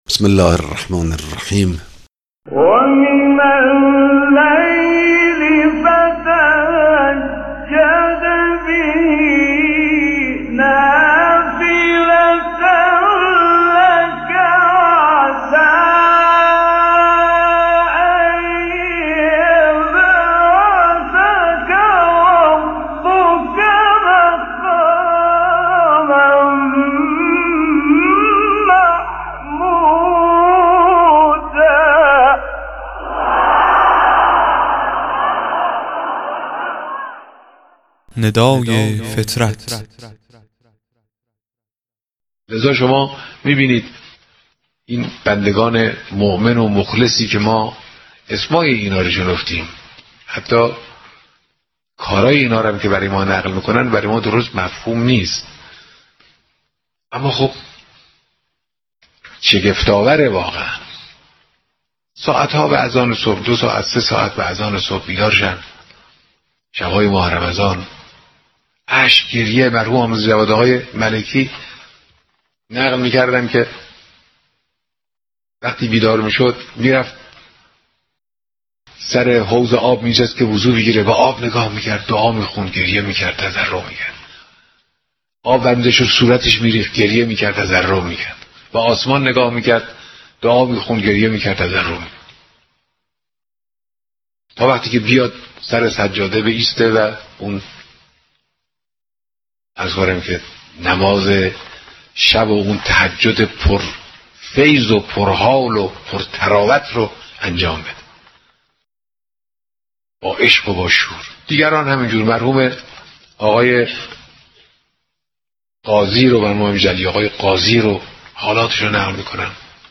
قطعه صوتی کوتاه و زیبا از امام خامنه ای در بیان حالات عارف بزرگ میرزا جواد ملکی تبریزی